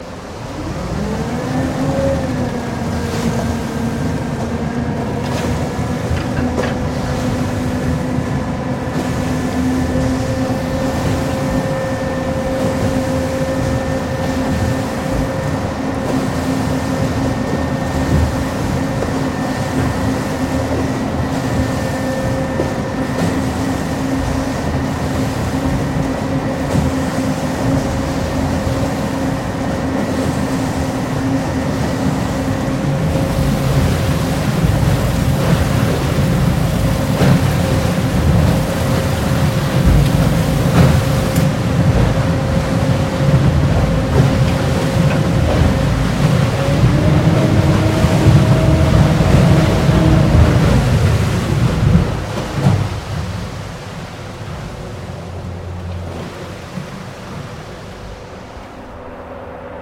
Качество записей проверено – никаких лишних шумов, только чистый звук техники.
Звук промышленного цементного насоса вытягивающего бетон из бетономешалки